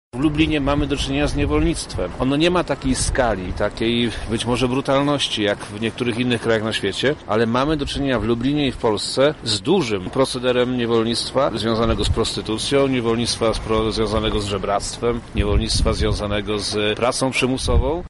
Wojewoda lubelski Przemysław Czarnek mówił dziś(11.03) o tym, że w Afryce niewolnictwo ma zdecydowanie większe rozmiary i przybiera najbardziej brutalny charakter.